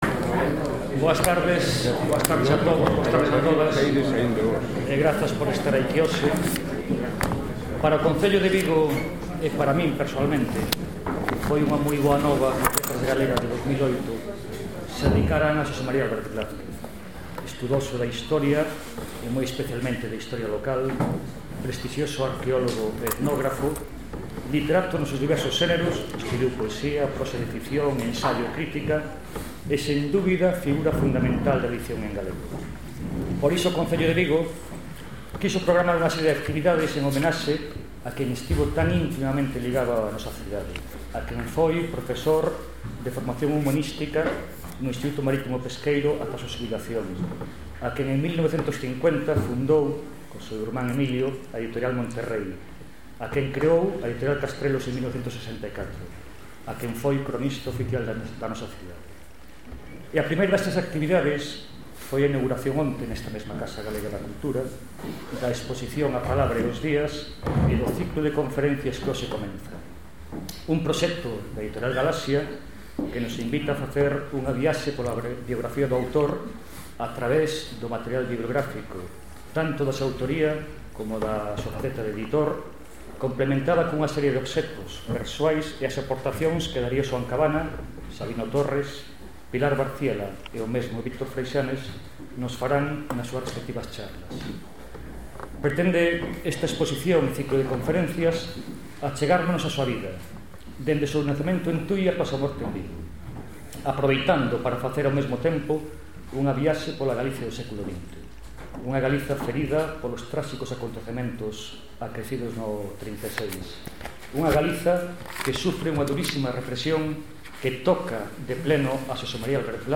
Vigo, Casa Galega da Cultura, 20 h. Acto dentro do ciclo A palabra e os días
Concelheiro de Cultura de Vigo